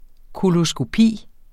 Udtale [ kolosgoˈpiˀ ]